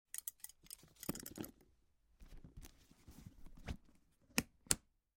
Звуки стетоскопа